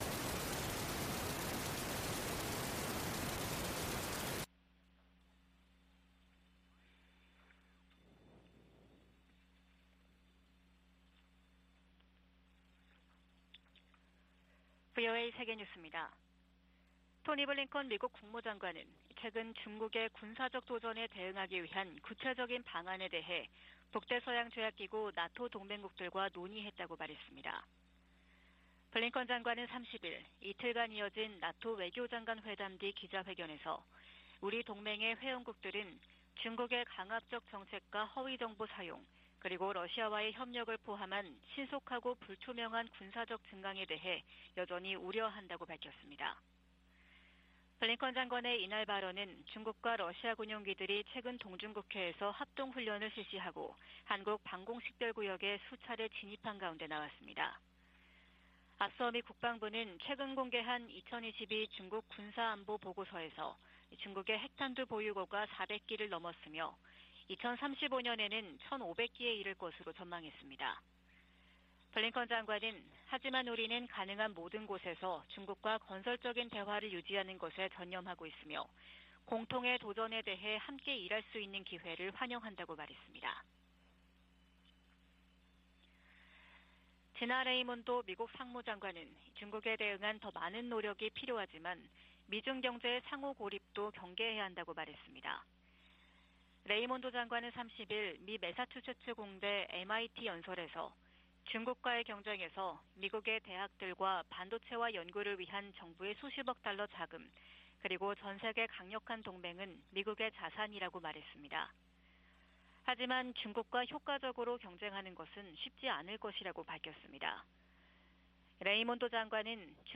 VOA 한국어 '출발 뉴스 쇼', 2022년 12월 2일 방송입니다. 바이든 행정부는 한일 동맹과의 긴밀한 공조 속에 한반도의 완전한 비핵화를 계속 추진할 것이라고 제이크 설리번 백악관 국가안보보좌관이 밝혔습니다. 북한과 중국, 러시아, 이란, 시리아 지도자들은 국내 산적한 문제에도 불구하고 국제사회에 위협을 가하는 ‘불량배와 독재자’라고 미치 맥코넬 상원 공화당 원내대표가 비난했습니다.